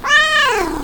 doc-audio-8 / cat /1.wav